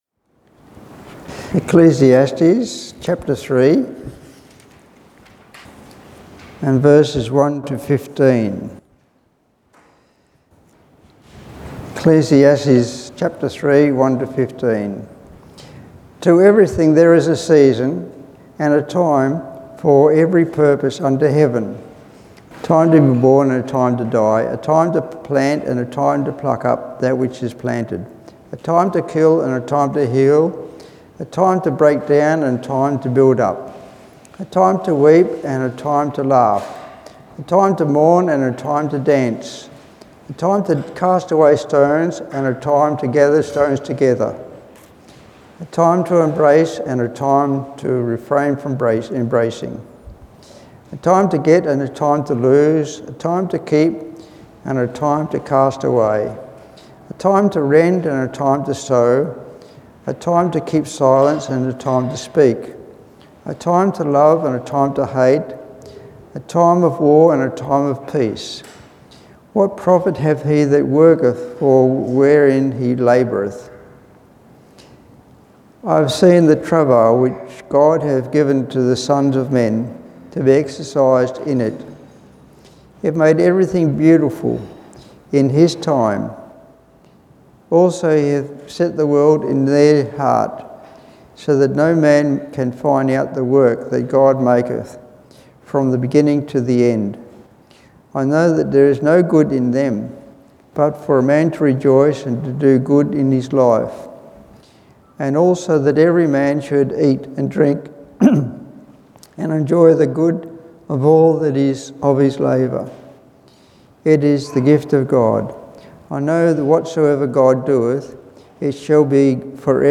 Passage: Ecclesiastes 3:1-15 Service Type: Evening Service